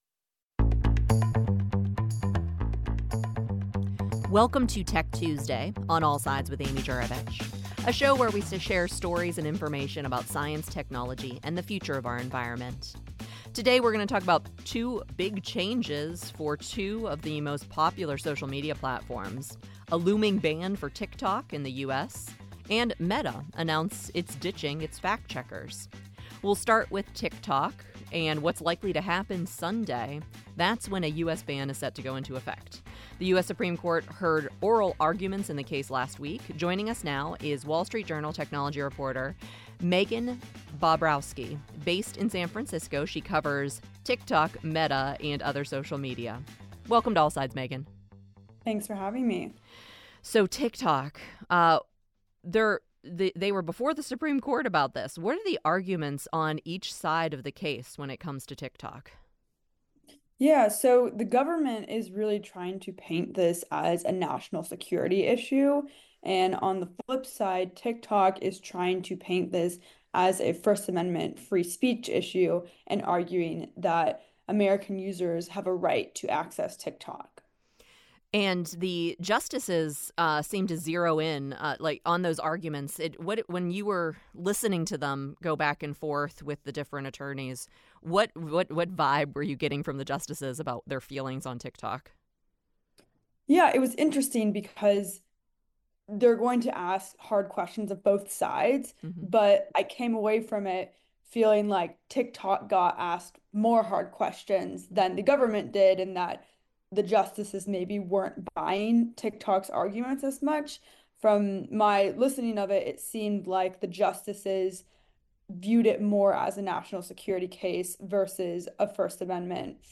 Untangled: Why is the healthcare system so complicated?. Weekly reporter roundtable.